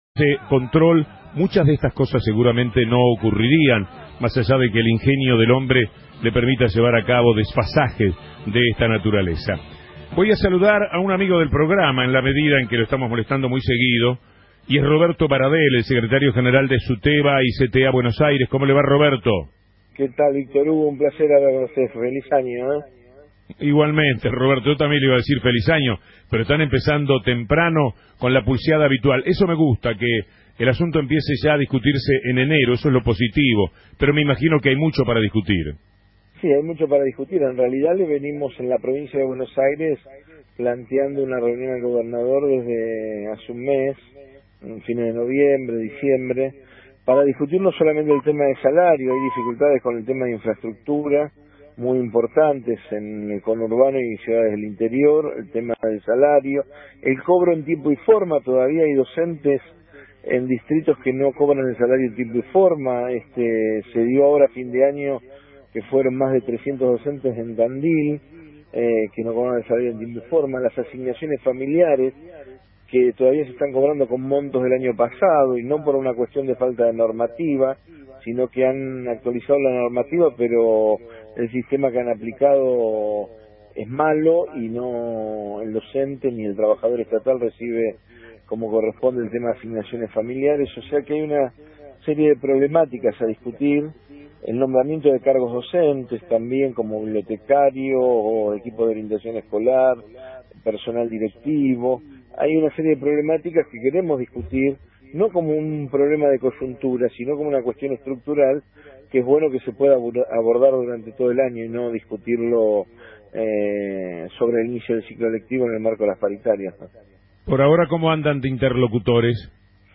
entrevista_a_roberto_baradel_por_victor_hugo_morales.mp3